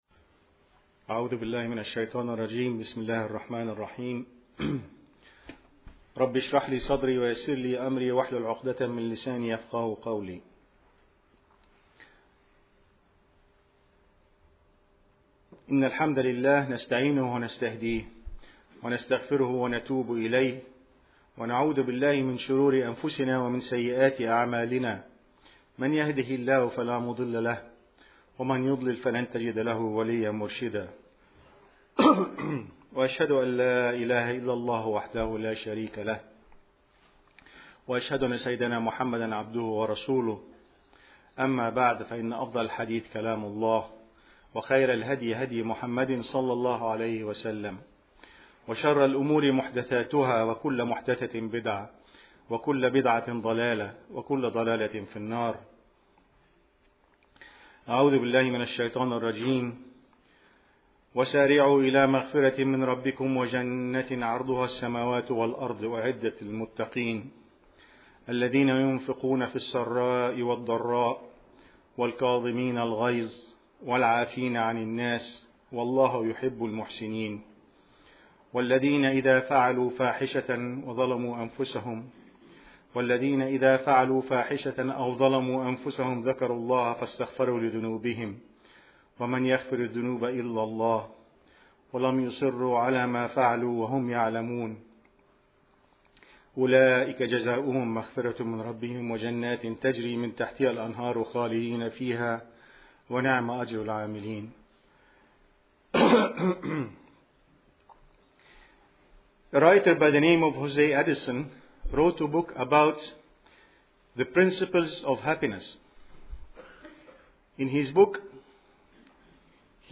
Audio Khutbah